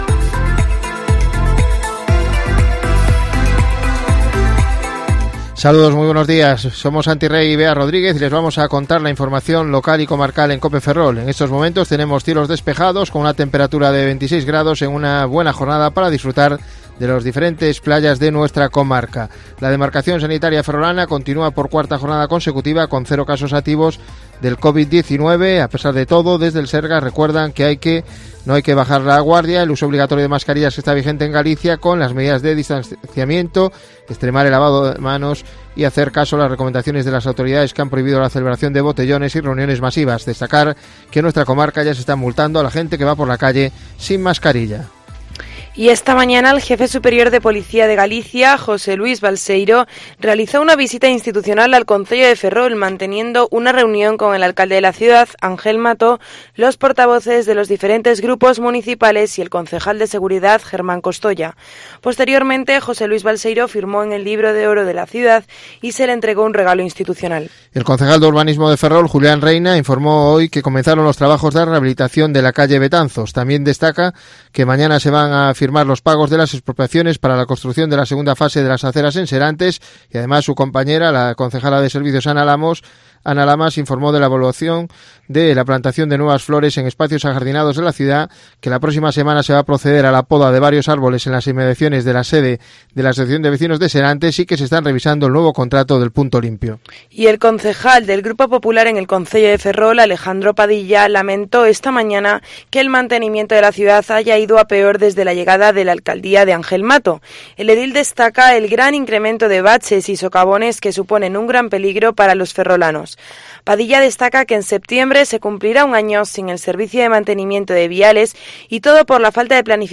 INFORMATIVO MEDIODÍA COPE FERROL (22 - JULIO)